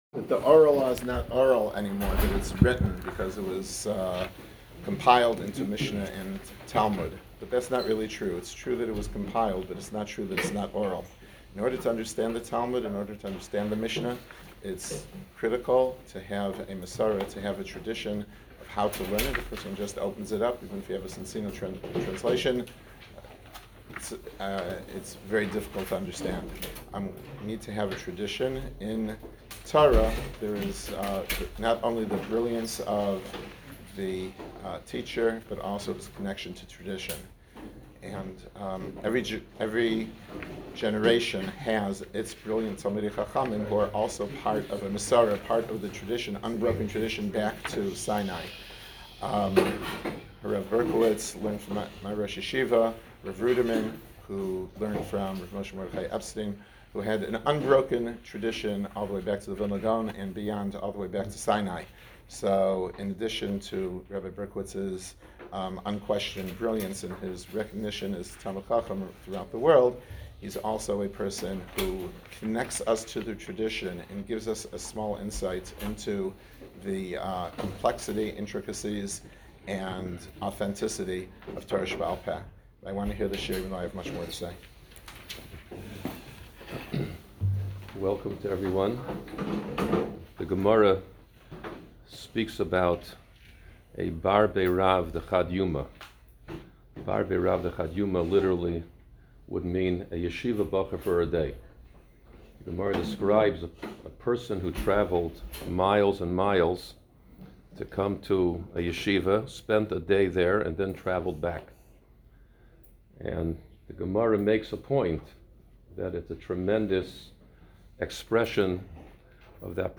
Campus, Special Lecture - Ner Israel Rabbinical College